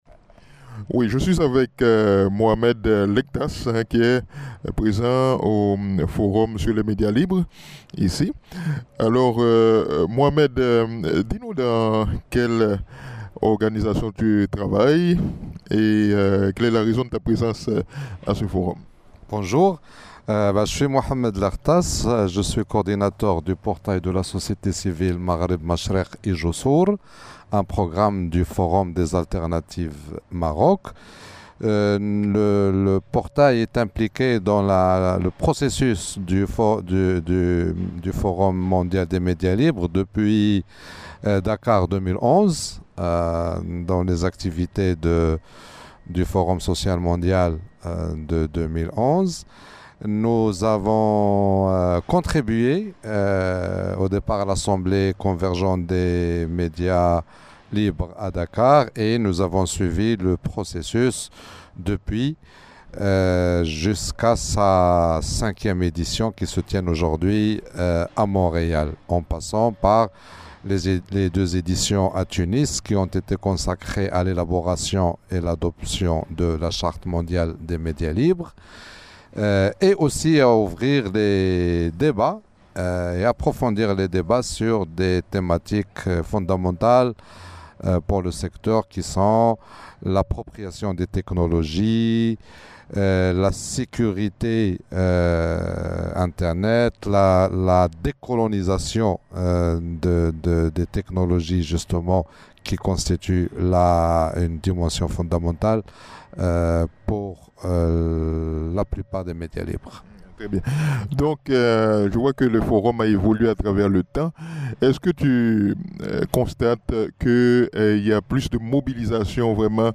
Type: Interview
192kbps Stereo